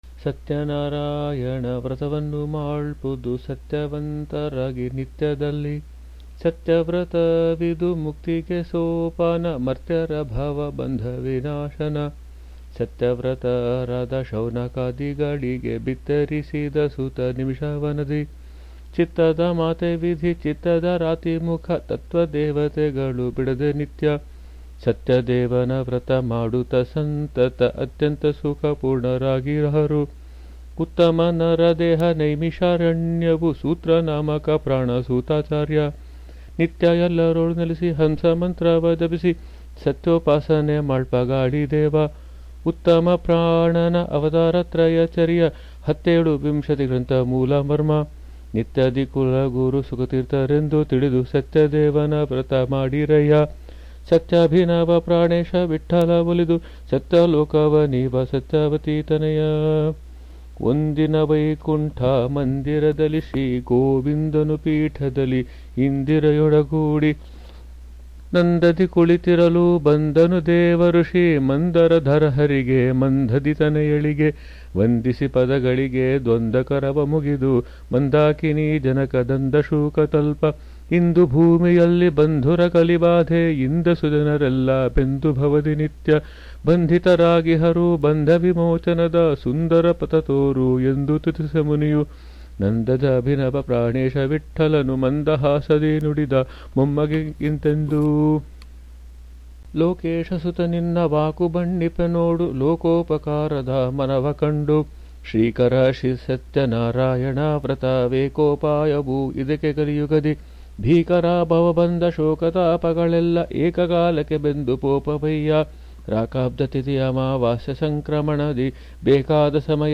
I have also recited the suladi in a simple audio format.